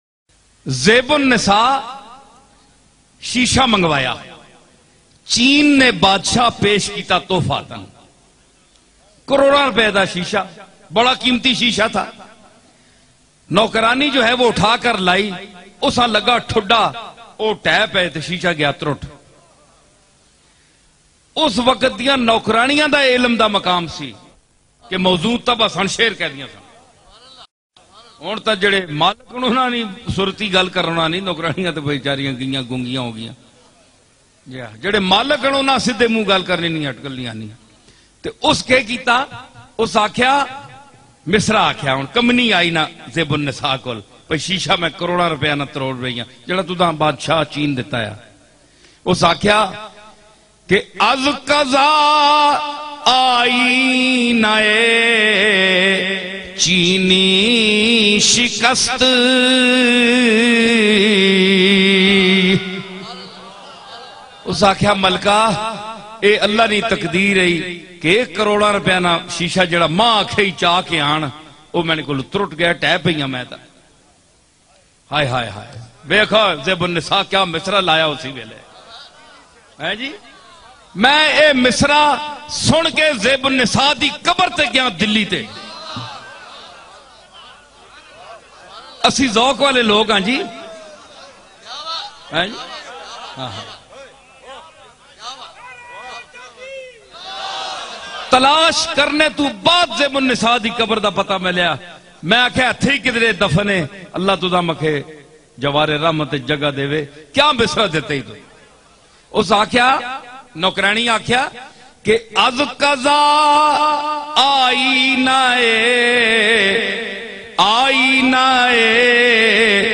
AENA e DIL Pir Naseeruddin Naseer bayan mp3